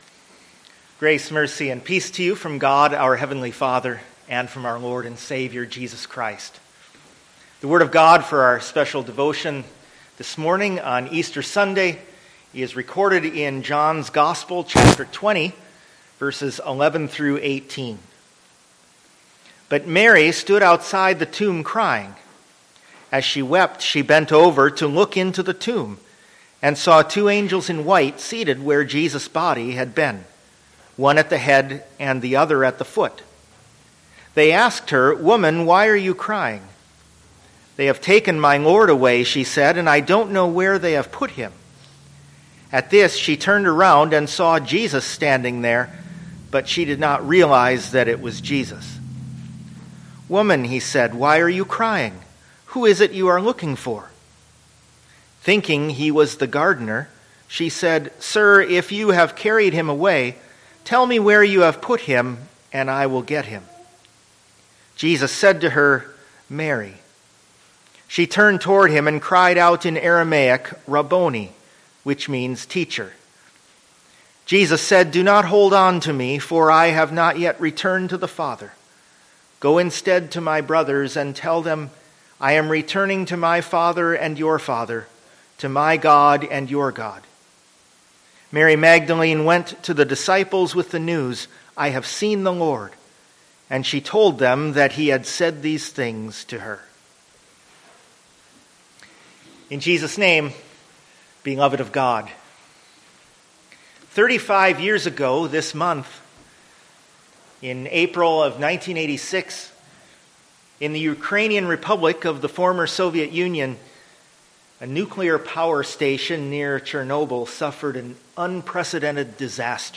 John 20:11-18 Service Type: Easter John 20:11-18